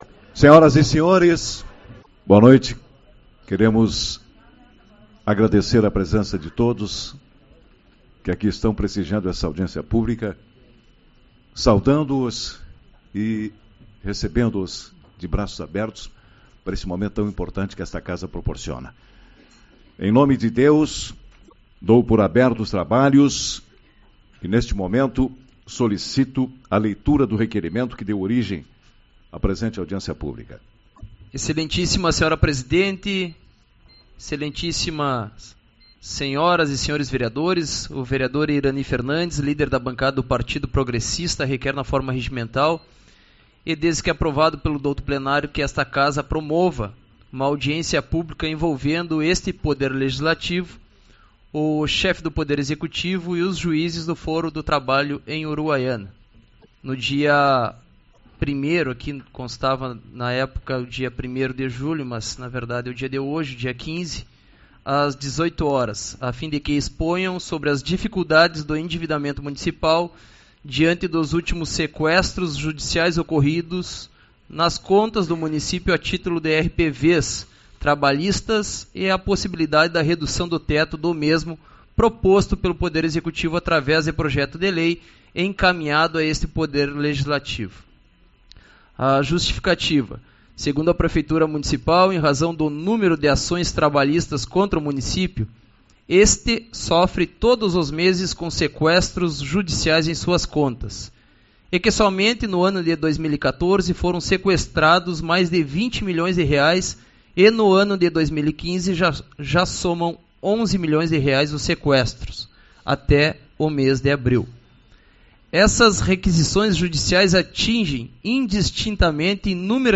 15/07 - Audiência Pública-RPVS